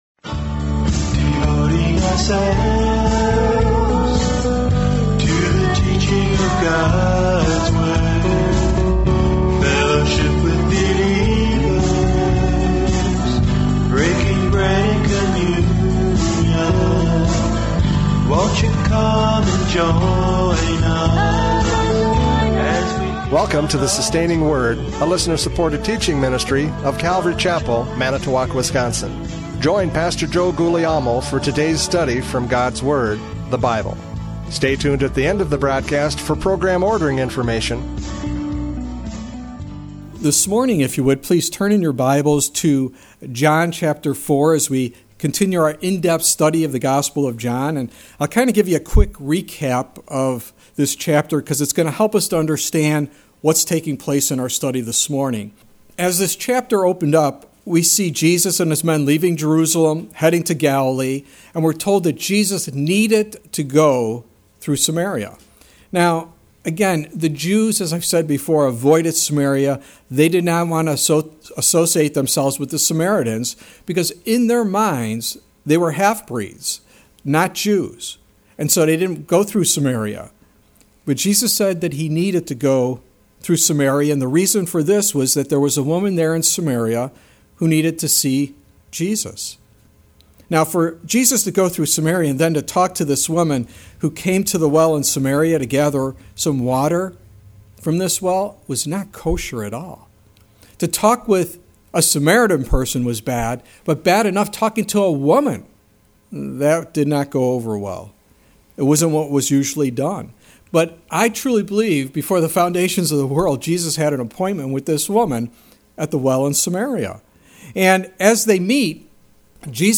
John 4:27-42 Service Type: Radio Programs « John 4:11-26 Living Water!